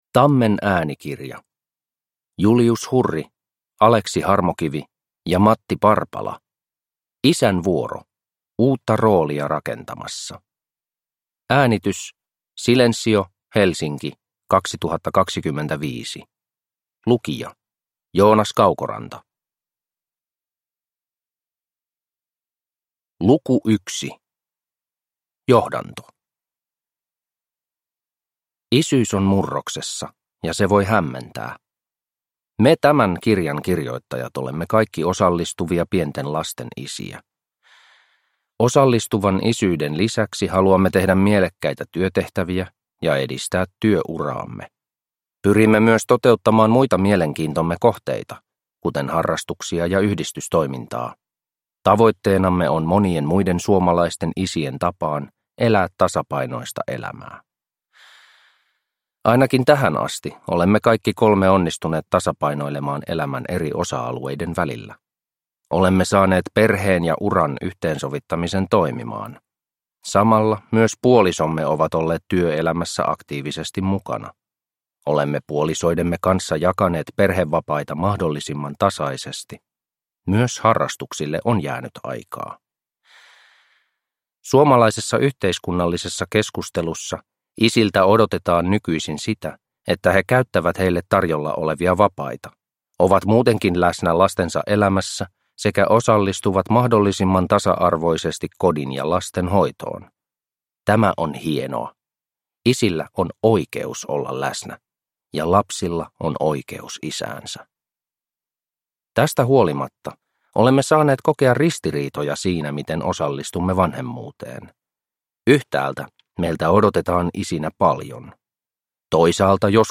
Isän vuoro – Ljudbok